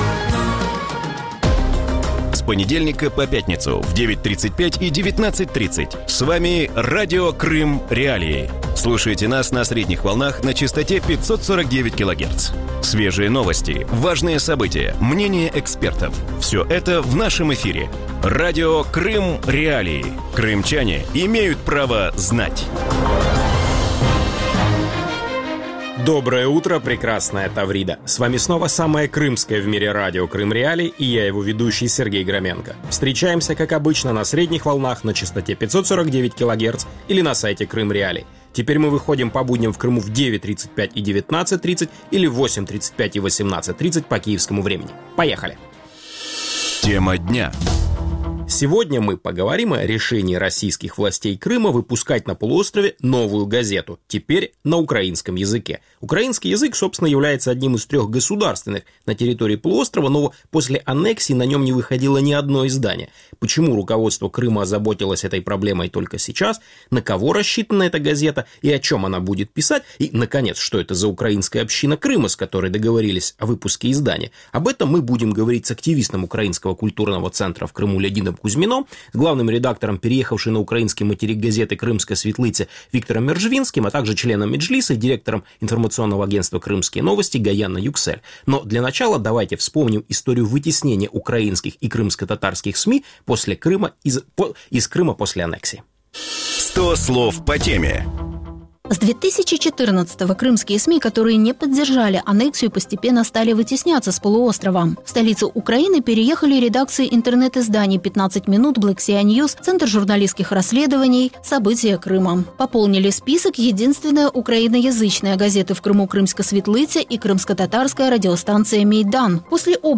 У ранковому ефірі Радіо Крим.Реалії говорять про намір російської влади Криму видавати на півострові нову газету українською мовою.